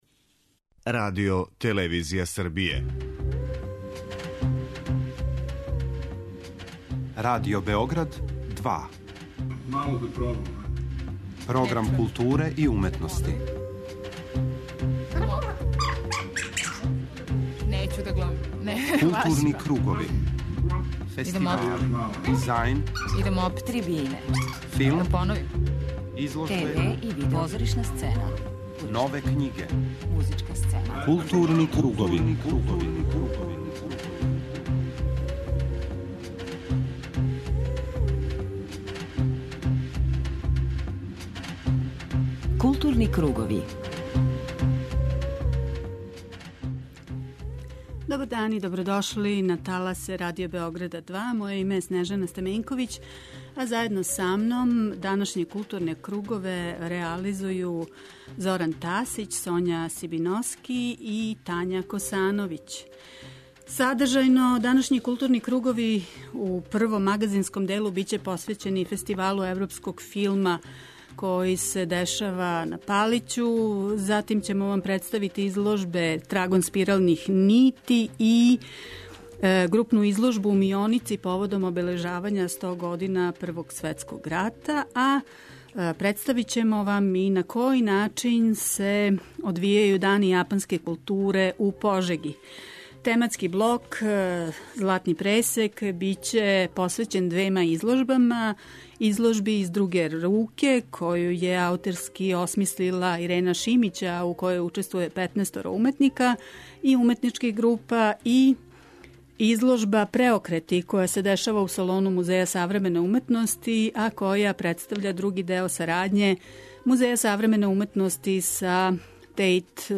преузми : 54.04 MB Културни кругови Autor: Група аутора Централна културно-уметничка емисија Радио Београда 2.